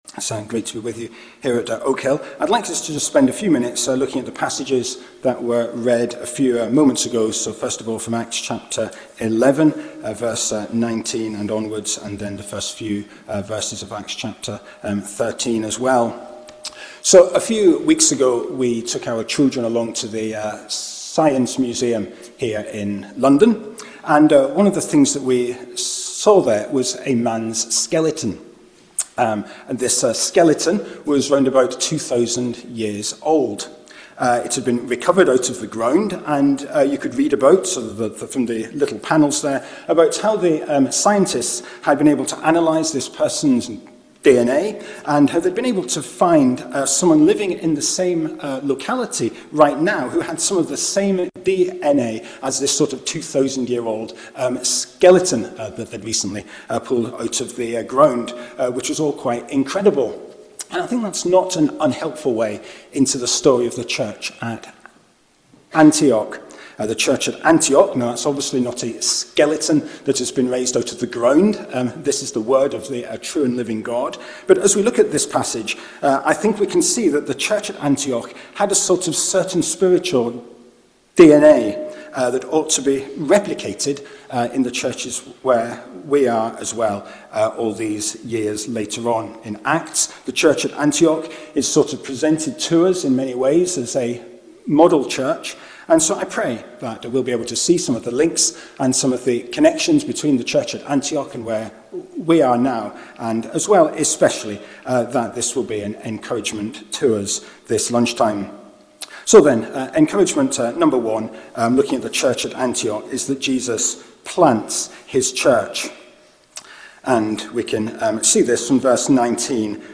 In this Oak Hill College Chapel message, we explore the “spiritual DNA” of the church at Antioch in Acts of the Apostles 11–13. Antioch was a diverse, strategic, international church — and a model of gospel health.